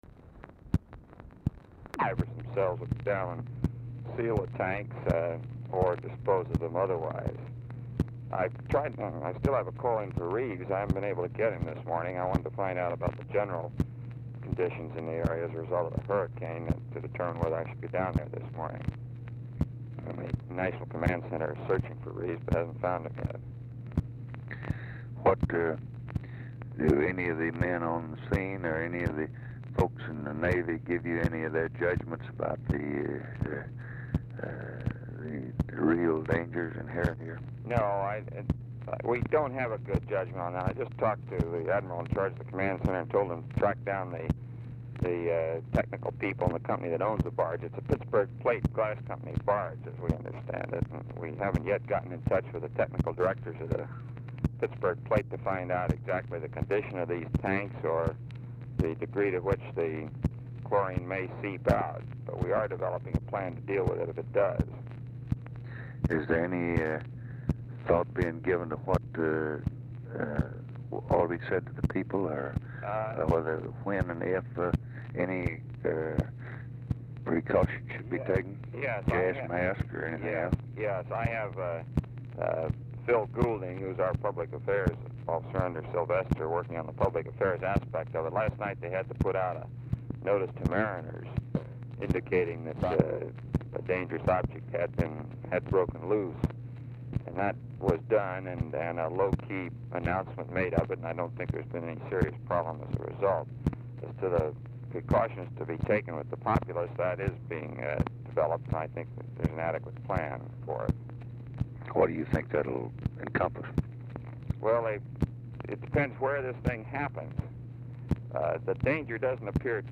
Telephone conversation # 8851, sound recording, LBJ and ROBERT MCNAMARA, 9/12/1965, 9:26AM?
RECORDING STARTS AFTER CONVERSATION HAS BEGUN; CONTINUES ON NEXT RECORDING
Format Dictation belt
Location Of Speaker 1 Mansion, White House, Washington, DC